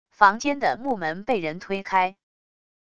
房间的木门被人推开wav音频